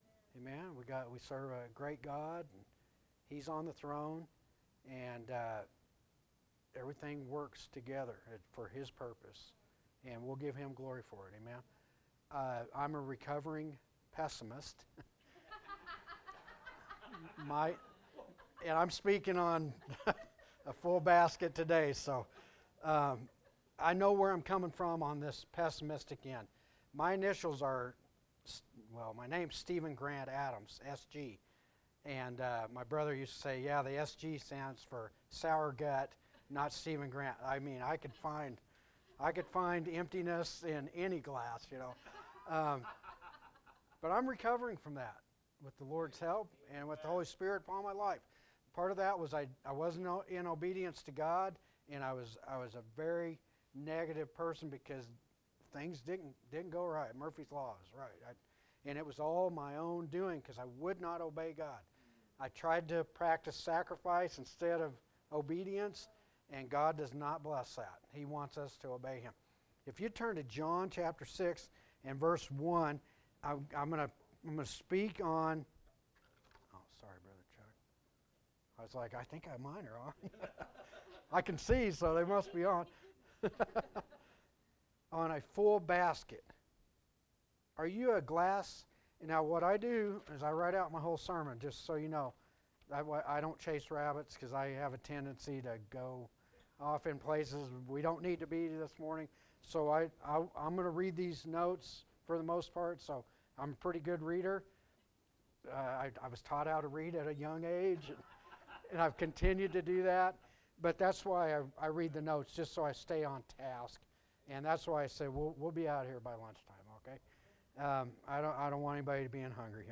A message from the series "Out of Series."